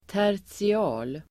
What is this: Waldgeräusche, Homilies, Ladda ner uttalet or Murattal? Ladda ner uttalet